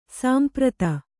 ♪ sāmprata